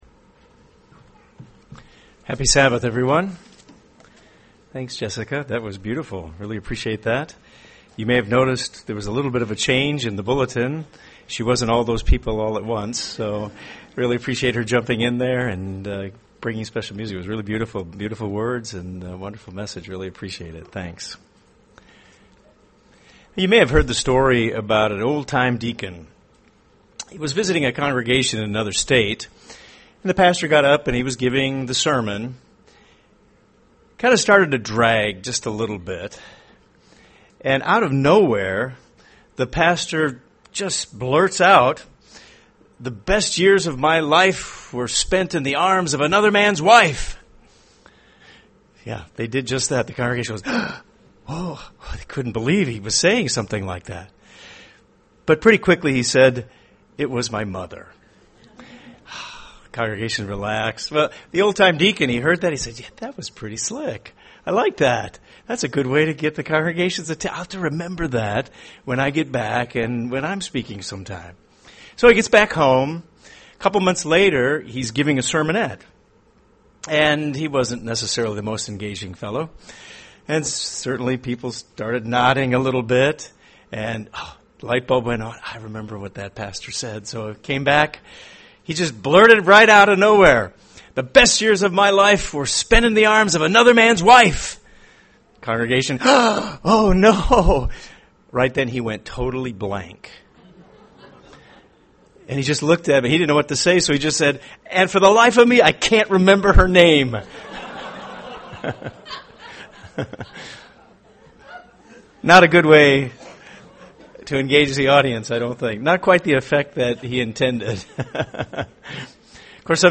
UCG Sermon Ordination Transcript This transcript was generated by AI and may contain errors.